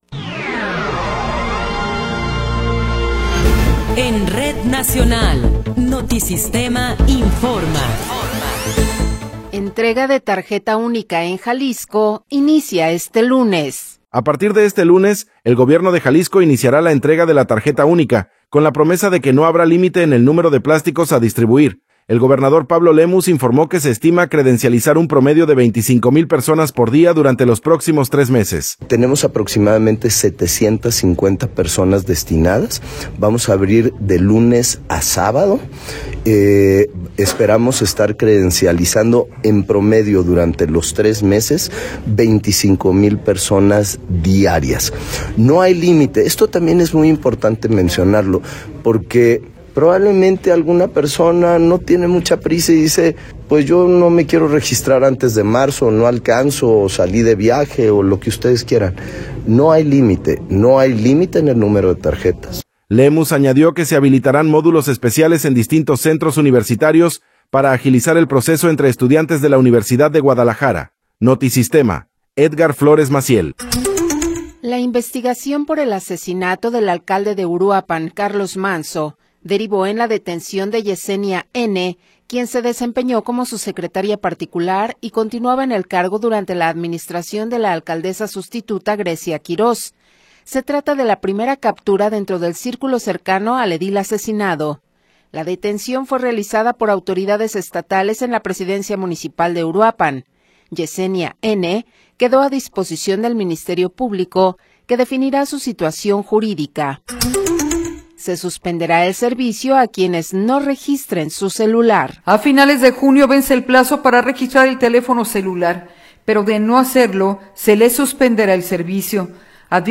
Noticiero 15 hrs. – 9 de Enero de 2026
Resumen informativo Notisistema, la mejor y más completa información cada hora en la hora.